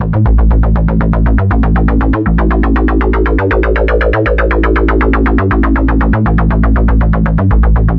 Bass03.mp3